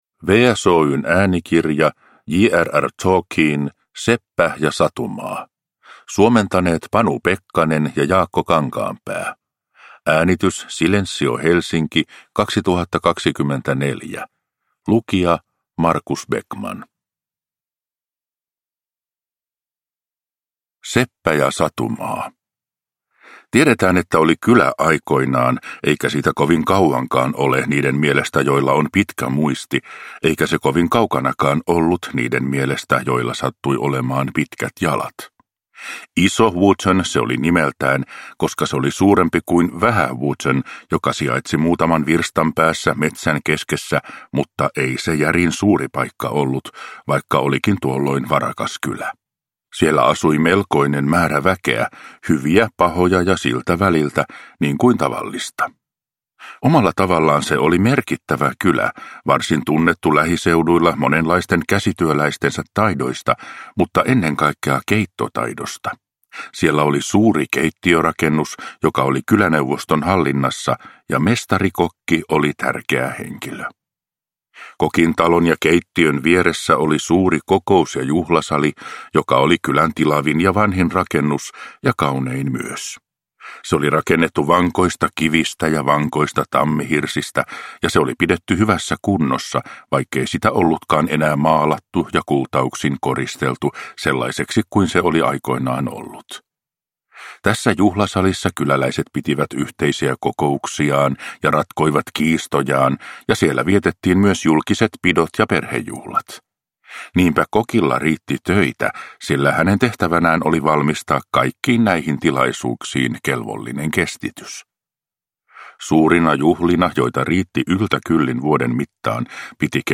Seppä ja Satumaa – Ljudbok